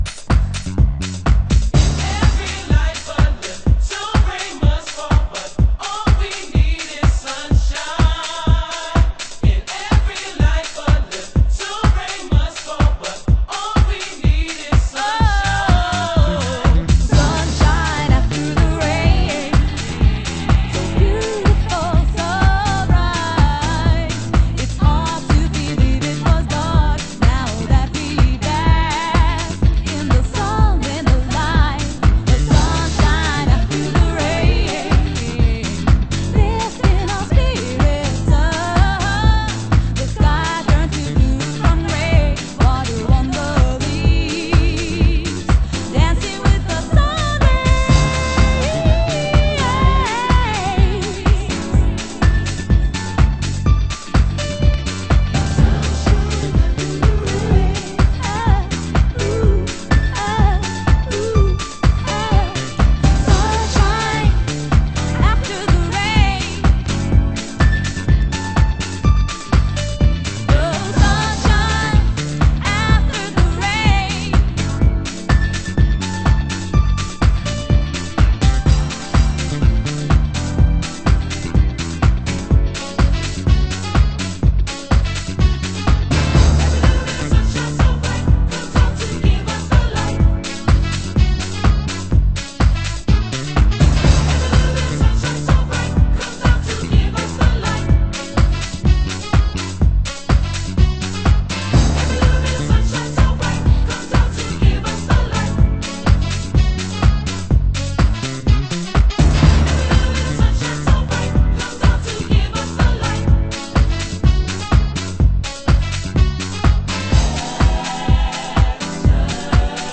Main Vocal